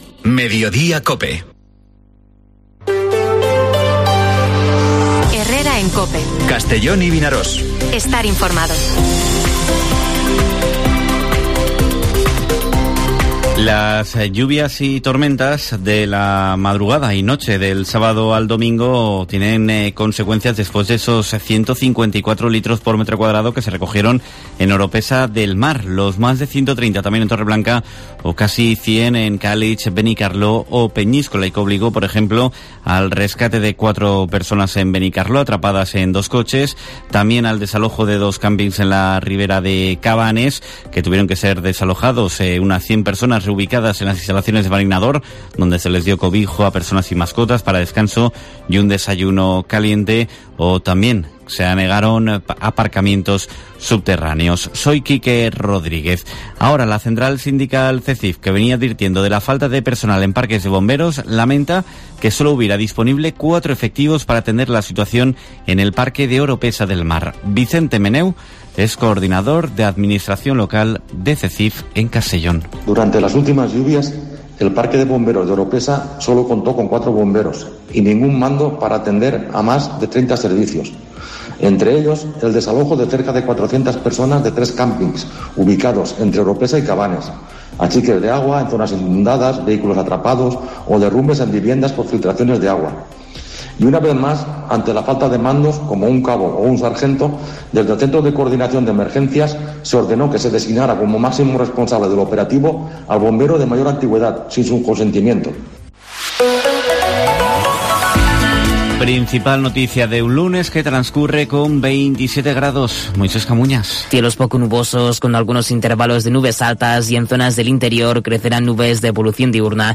Informativo Mediodía COPE en la provincia de Castellón (19/09/2022)